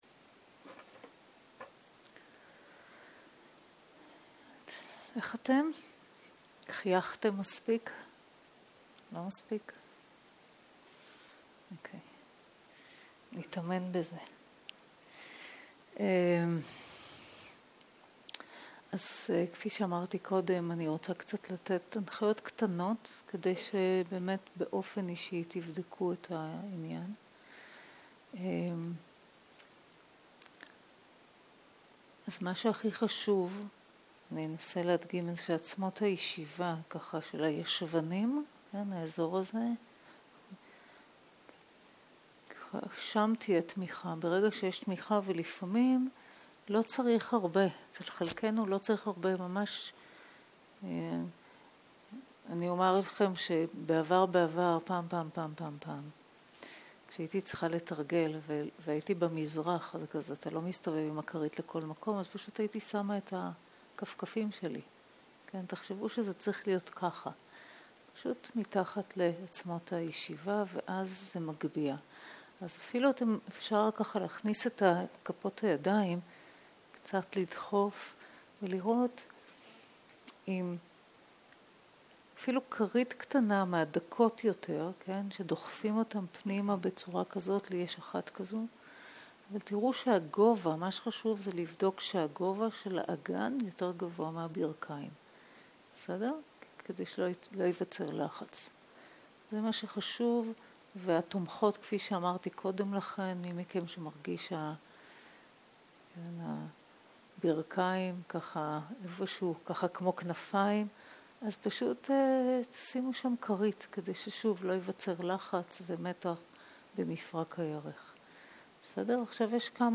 ערב - הנחיות מדיטציה - תנוחת ישיבה ותשומת לב לגוף ולנשימה - הקלטה 1 0:00 0:00 סוג ההקלטה: סוג ההקלטה: שיחת הנחיות למדיטציה שפת ההקלטה: שפת ההקלטה: עברית